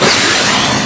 Noisepack " 噪声剪辑平移
描述：各种类型和形式的audionoise集合的一部分（有待扩展）
Tag: 配音 毛刺 噪声 噪声 配音 柔软